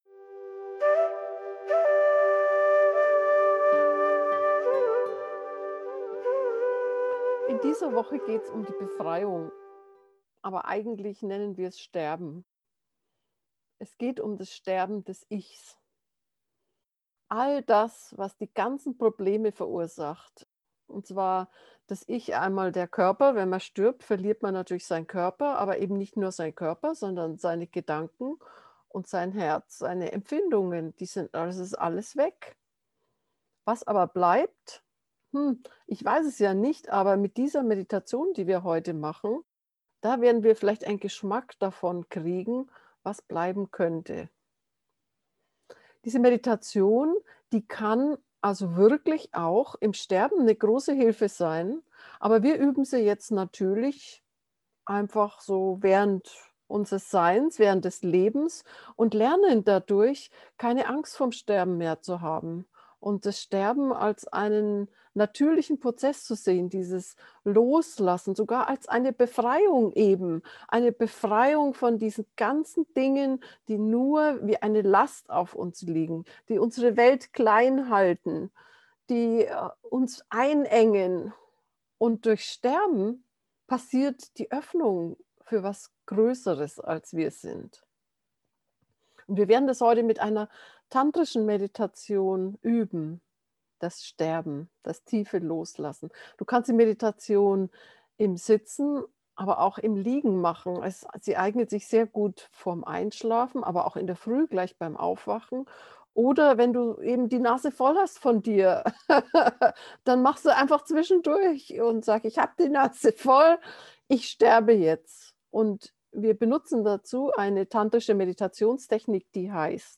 sterben-befreiung-gefuehrte-meditation.mp3